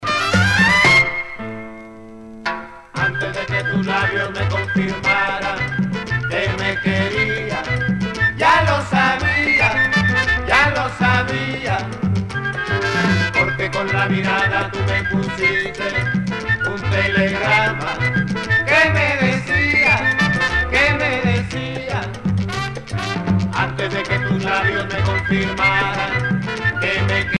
danse : guaracha
Pièce musicale éditée